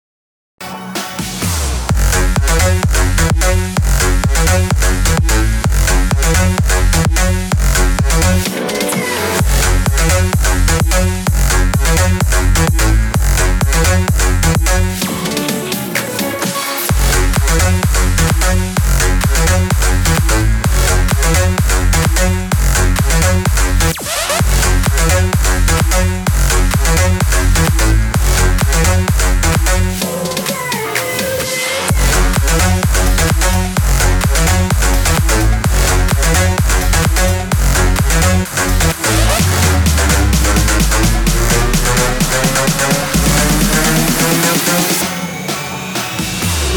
• Качество: 192, Stereo
громкие
EDM
Стиль: electro house